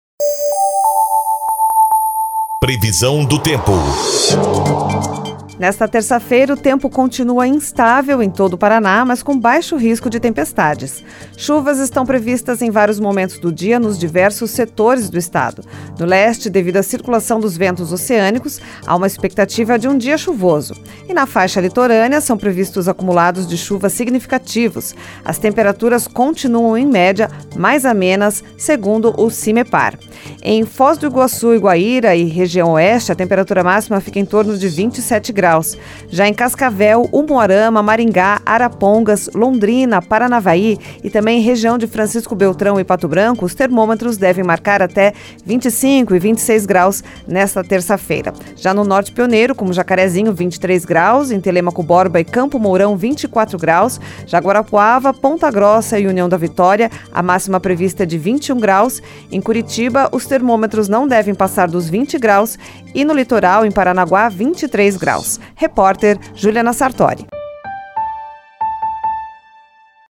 Previsão do Tempo (23/01)